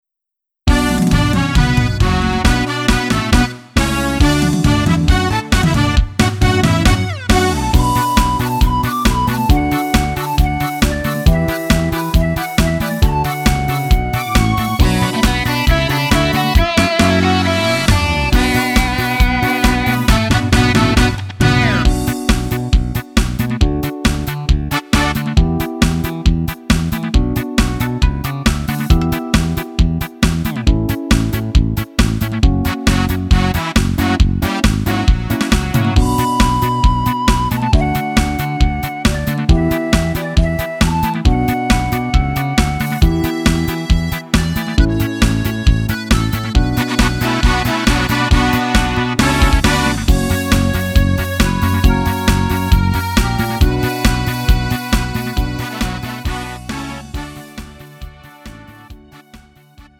음정 -1키 3:19
장르 구분 Lite MR